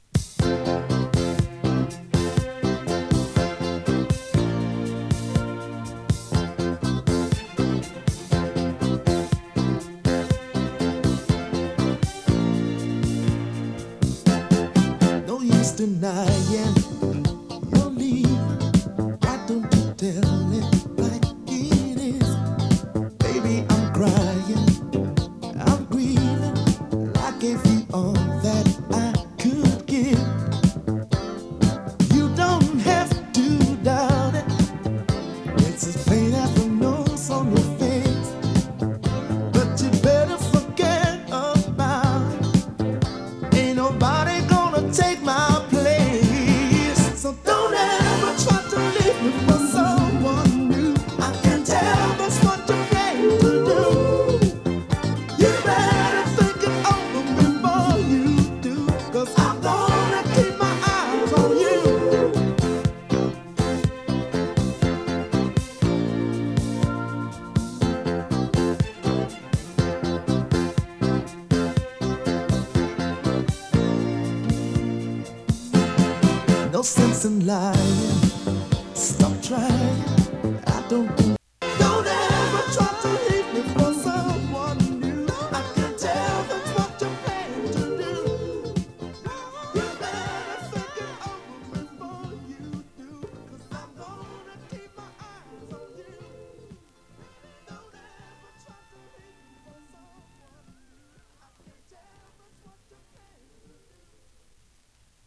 ＊音の薄い部分で時折軽いチリパチ・ノイズ。